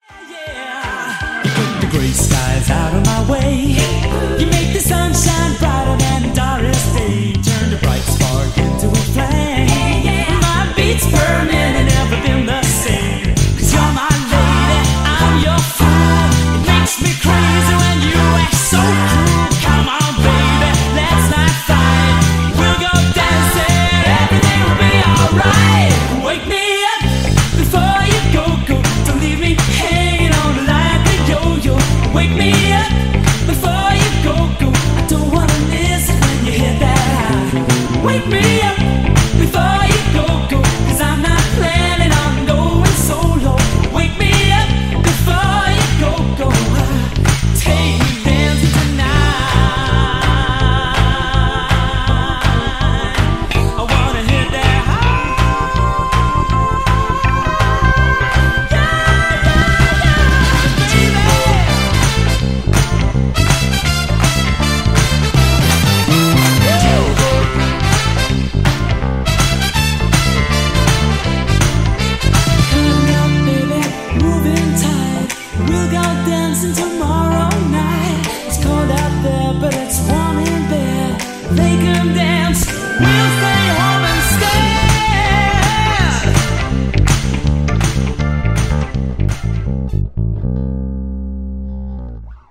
Nicht wundern, im Refrain ist der Groove etwas "gewöhnungsbedürftig"; wollt mal was ausprobieren... Diese Extratöne im Mittelteil (also Bb-A) find ick sehr cool :)